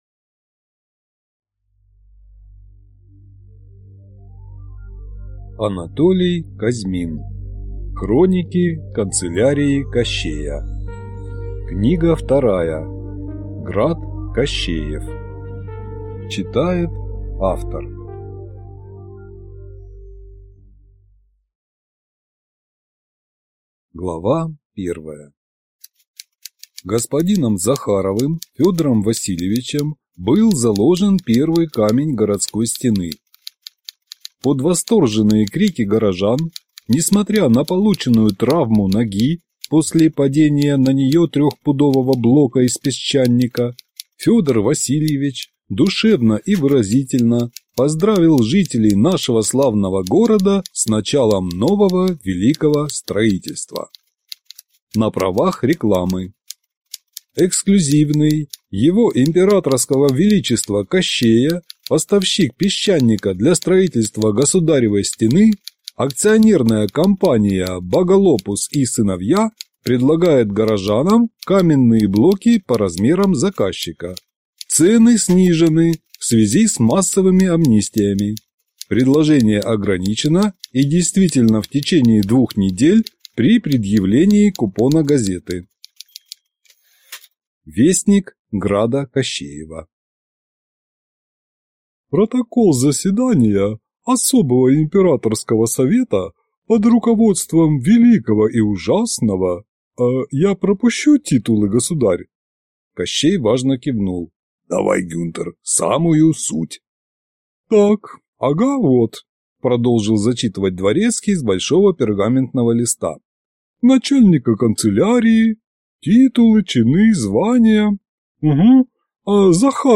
Аудиокнига Град Кощеев | Библиотека аудиокниг